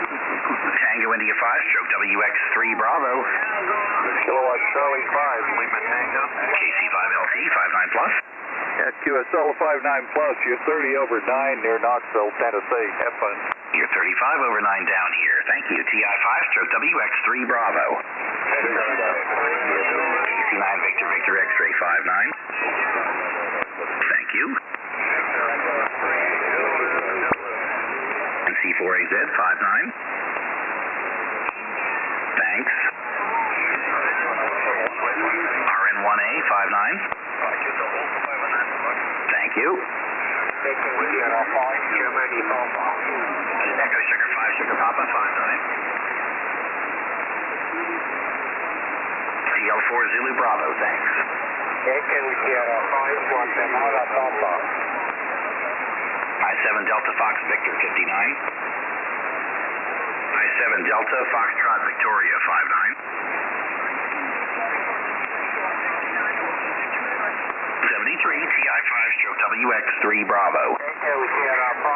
21MHz SSB